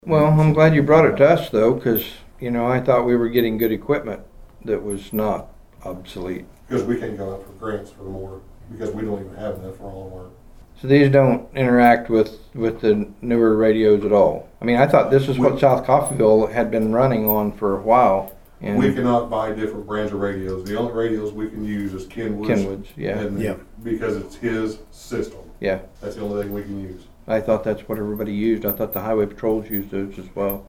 The Nowata County Commissioners met for a regularly scheduled meeting on Monday morning at the Nowata County Annex.
Commissioner Troy Friddle and Gibson went back-and-forth on updated radio systems.